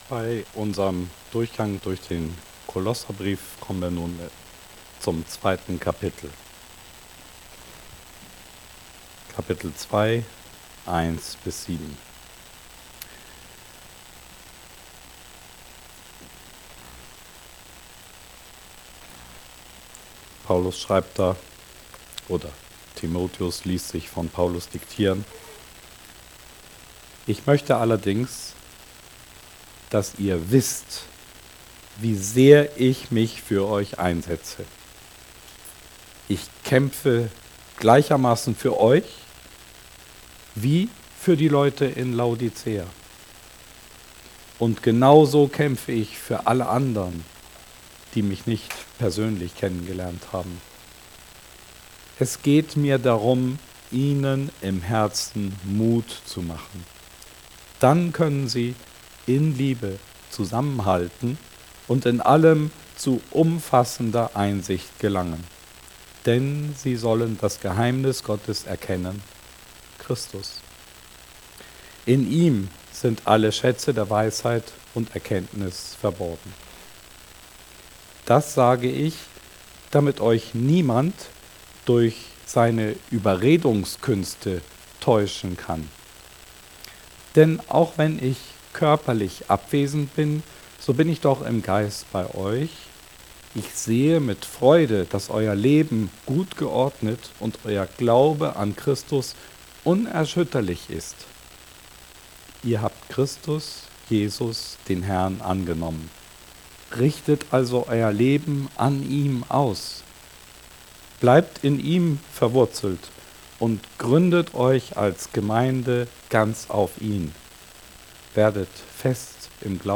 Die Predigt kann auch im Predigt-Blog auf der Webseite nachgelesen werden.